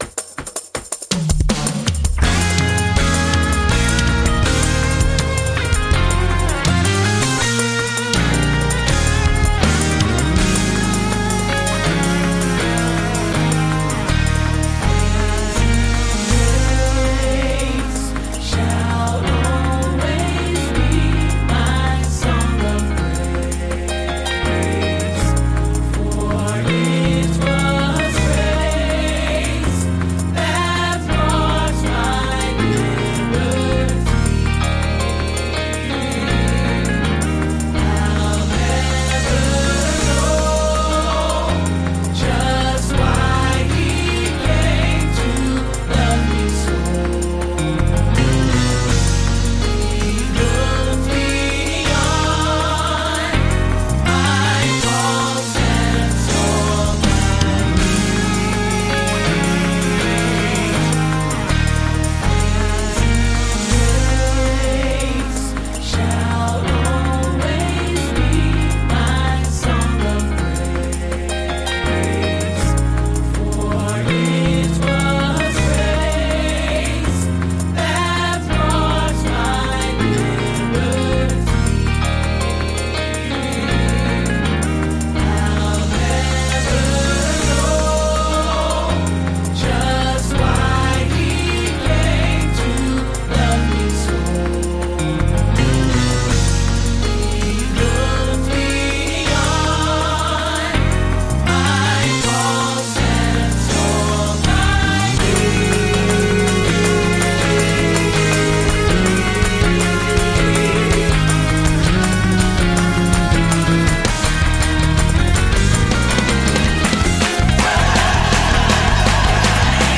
Music Ministry – Women’s Day 2025 – Beth-El Temple Church